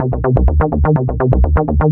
BL 125-BPM C.wav